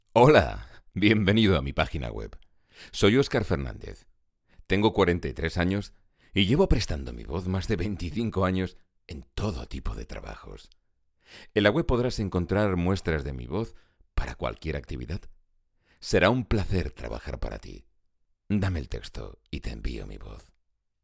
VOZ GRAVE, BAJO.
kastilisch
Sprechprobe: Sonstiges (Muttersprache):